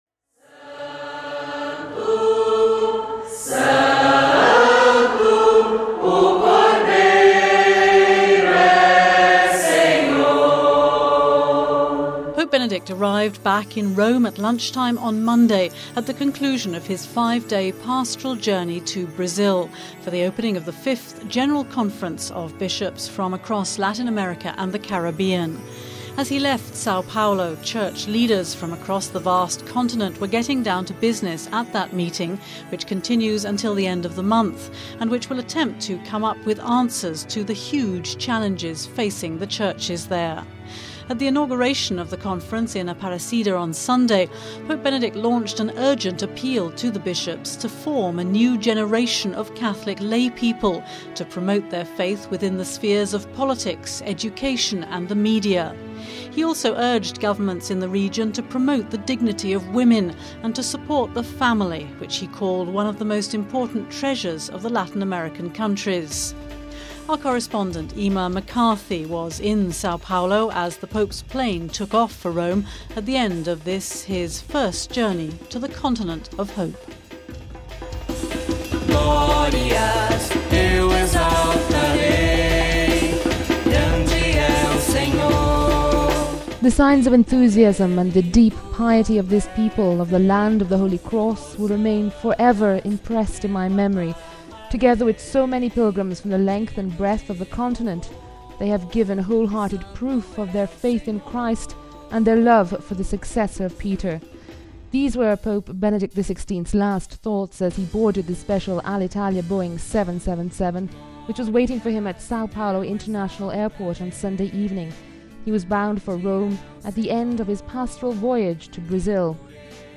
As Pope Benedict arrives back in Rome at the end of his pastoral journey to Brazil our correspondant in Sao Paolo recalls some of the highlights of this visit to the 'continent of hope'..